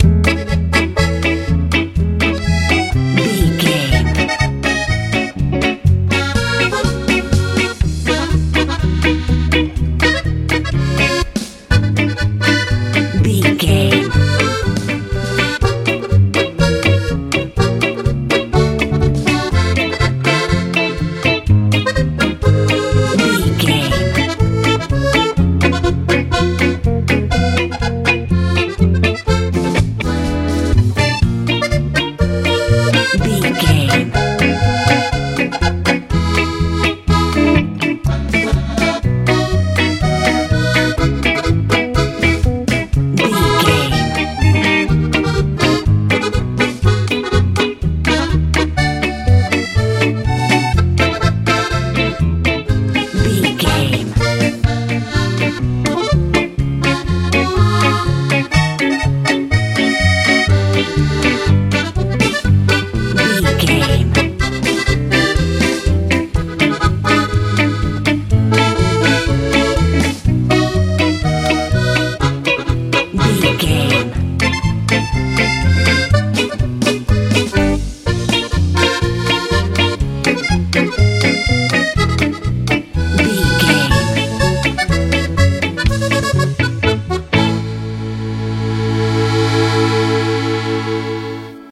Ionian/Major
C♯
hopeful
joyful
bass guitar
drums
electric guitar
accordion